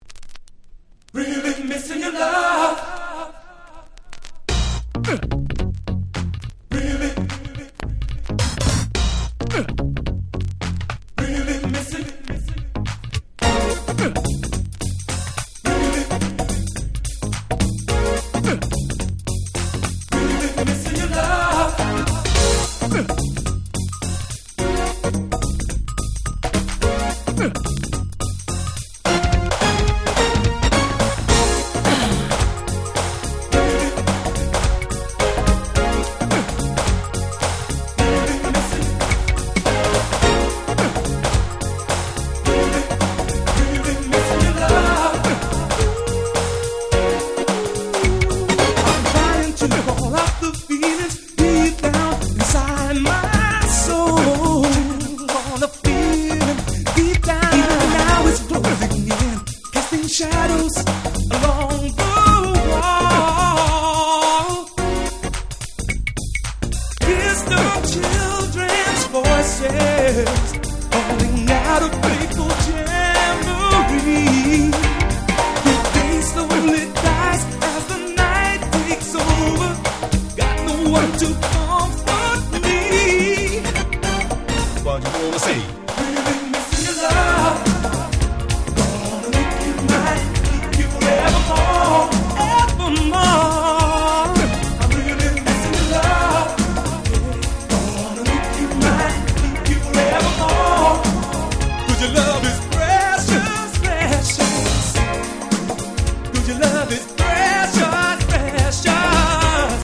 91' Very Nice UK Street Soul / New Jack Swing !!
ハネたBeatにSmoothな男性Vocalが100点満点！！
Ext Club Mix
90's ニュージャックスウィング